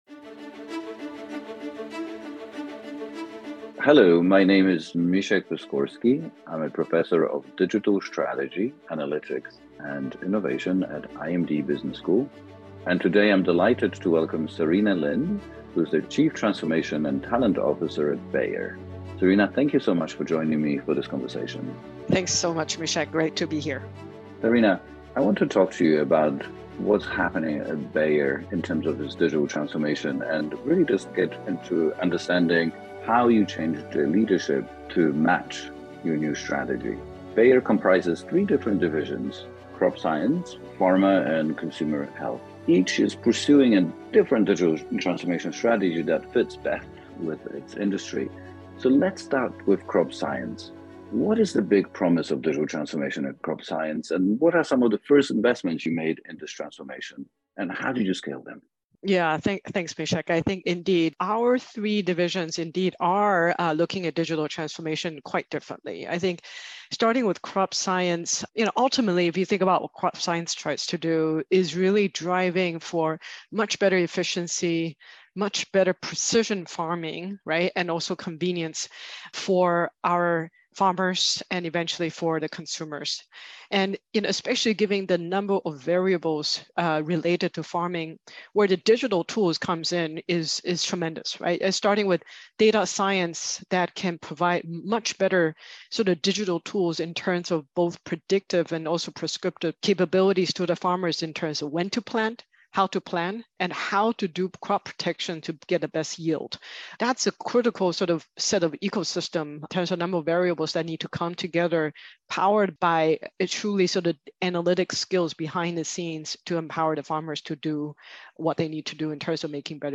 The Interview Podcast Series